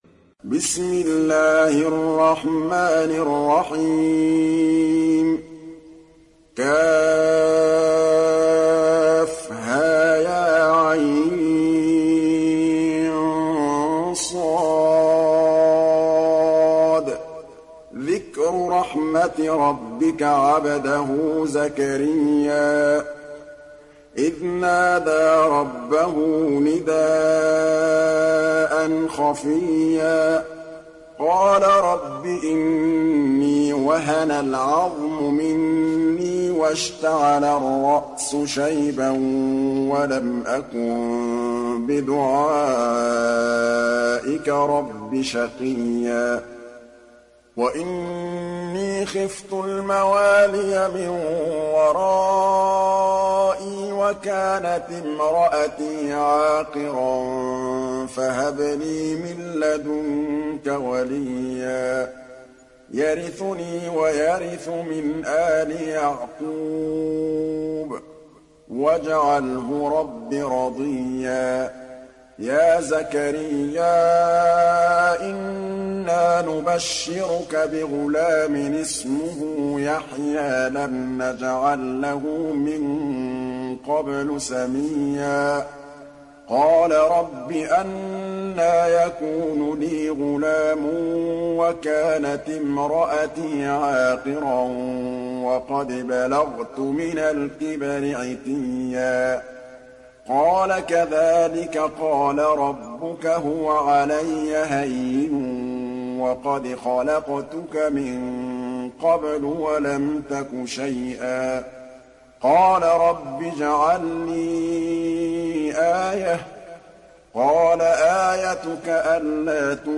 Meryem Suresi İndir mp3 Muhammad Mahmood Al Tablawi Riwayat Hafs an Asim, Kurani indirin ve mp3 tam doğrudan bağlantılar dinle